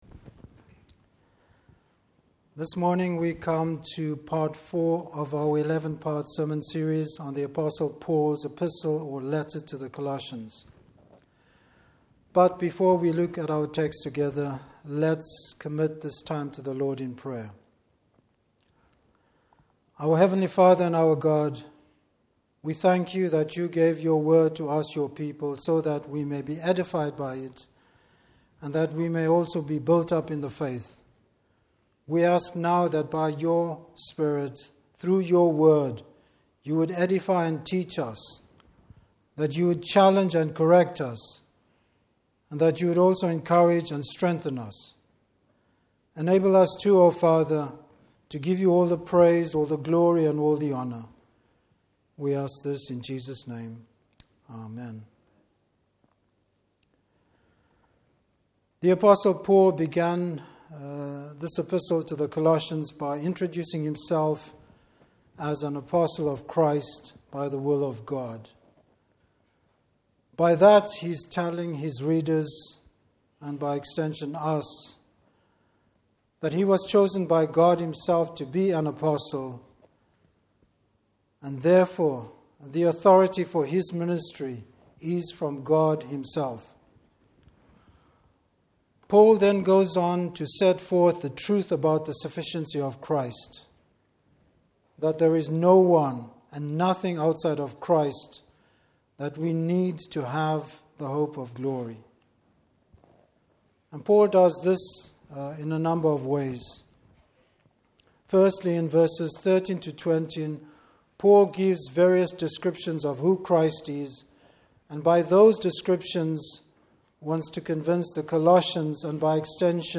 Service Type: Morning Service Paul wishes the Colossian Church to know more of the wisdom and knowledge found in Christ.